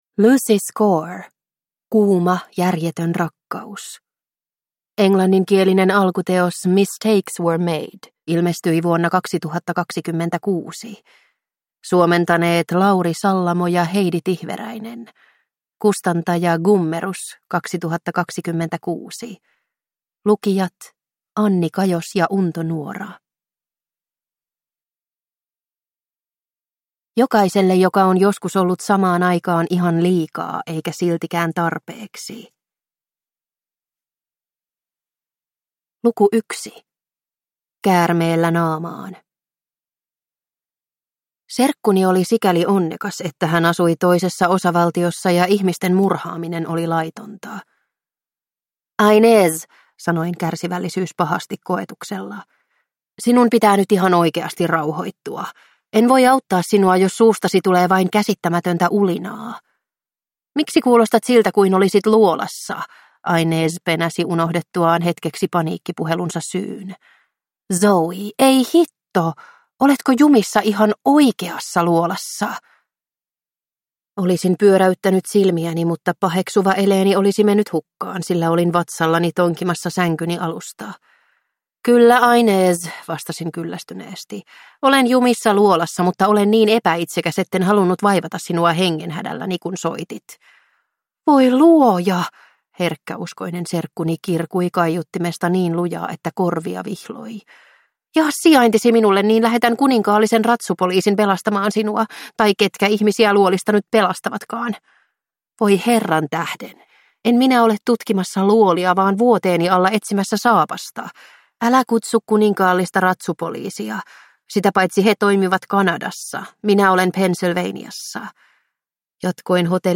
Kuuma, järjetön rakkaus – Ljudbok